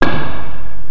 00017_Sound_Wite-Hit.mp3